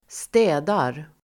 Uttal: [²st'ä:dar]